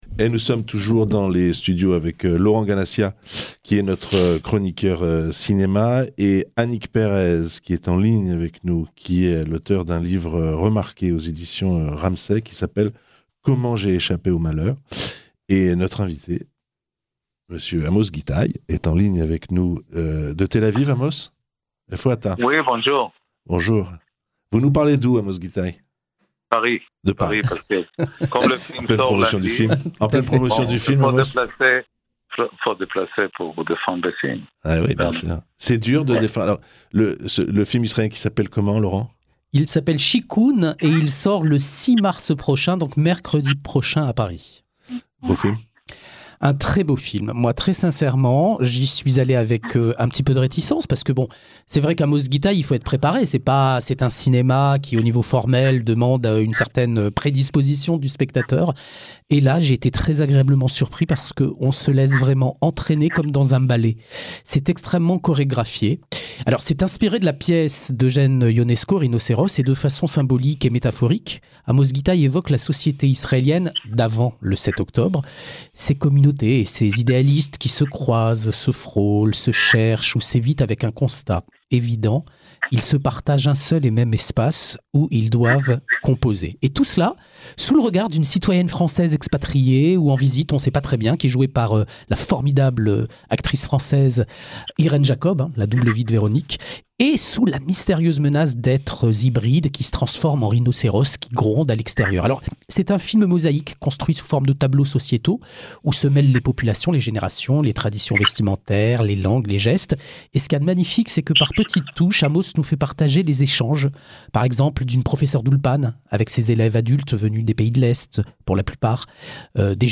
Interview de Amos Gitaï